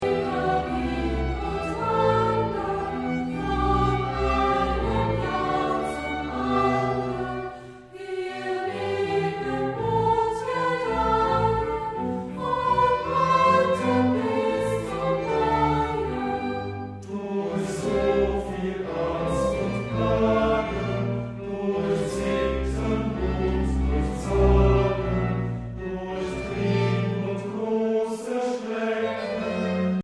Instrumentalsätze für variable Besetzungen